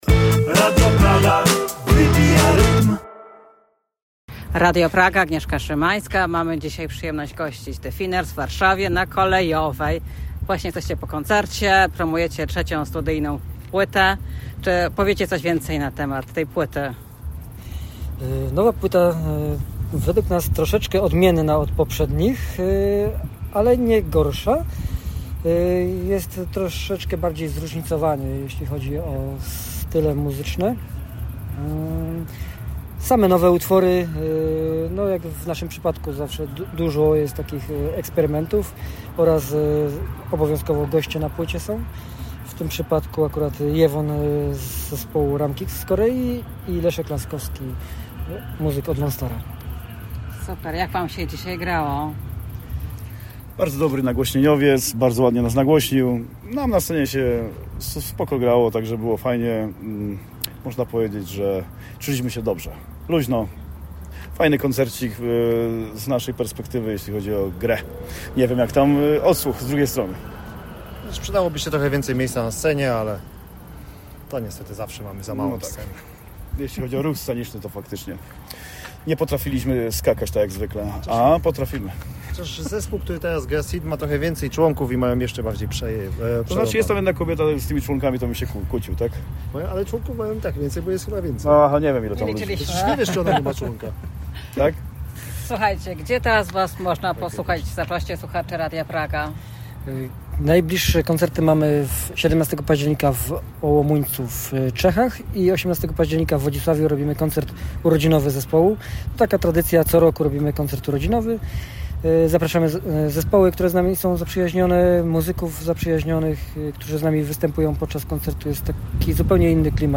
Zapraszamy do wysłuchania po koncertowej rozmowy z zespołem.
The-Thinners-wywiad-dla-Radio-Praga.mp3